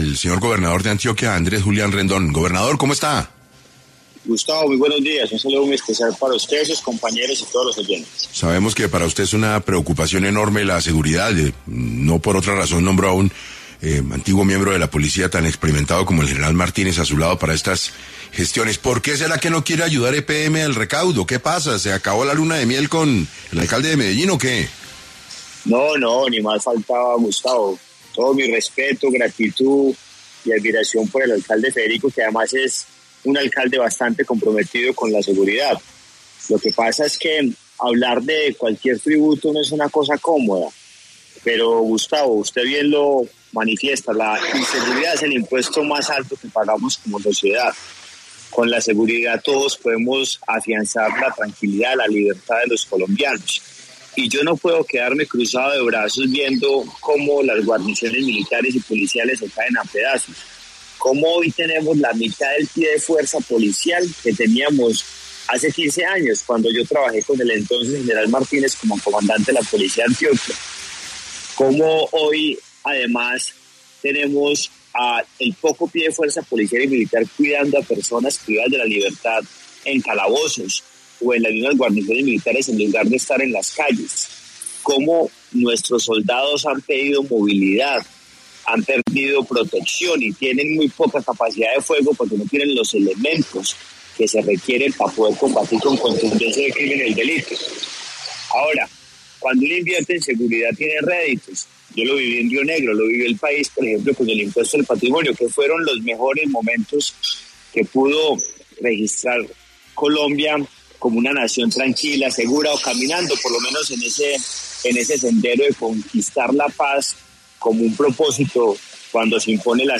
Andrés Julián Rendón, gobernador de Antioquia, explicó en 6AM, por qué insiste en cobrar la ‘tasa de seguridad” y el actuar de EPM
Para el programa de 6AM del viernes, 6 de diciembre, se conectó Andrés Julián Rendón, gobernador de Antioquia, para hablar sobre como se está manejando la ‘tasa de seguridad” con EPM.